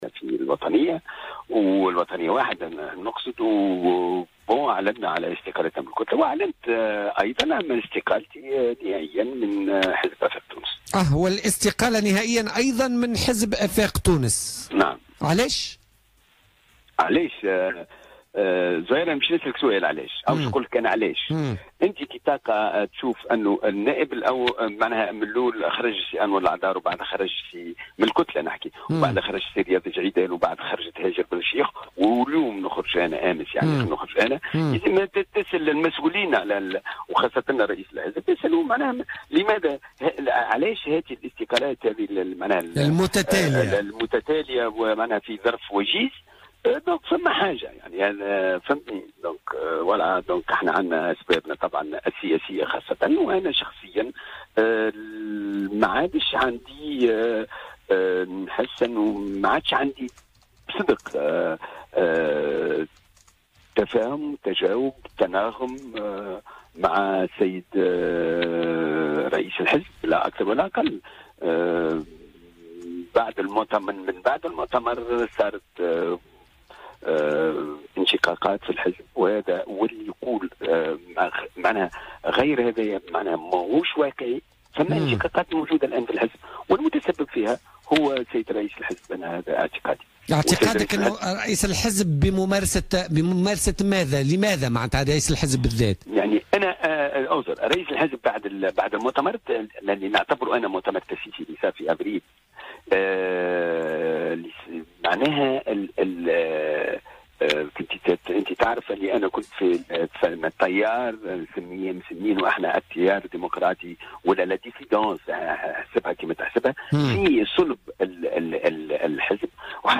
وقال النائب، ضيف بوليتيكا، اليوم الأربعاء، إن صاحب تلك البضائع تقدم منذ سنة 2011 لوزارة التجارة من أجل إتلاف تلك المواد، دون أن يتمكن من ذلك بسبب تعقيد الإجراءات.